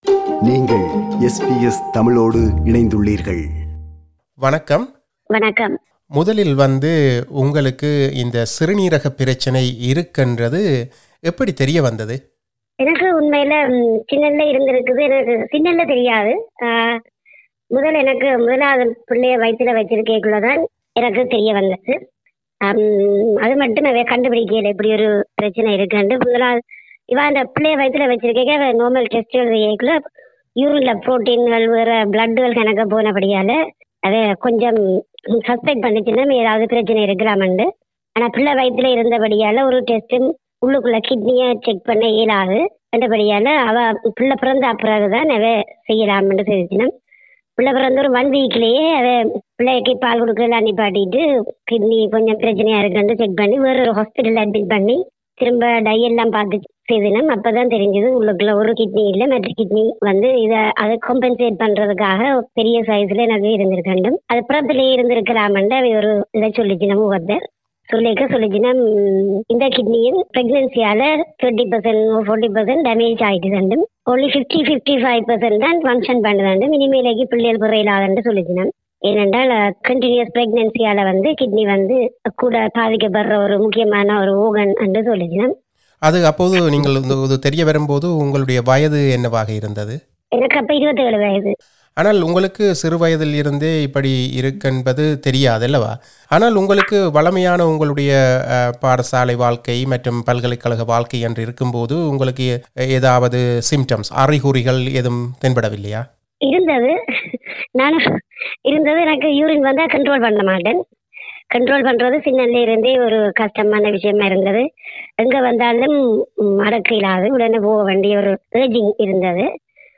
A Tamil woman (doen't want to be identified) who is battling with kidney disease for many years and waiting for a donor, shares her story…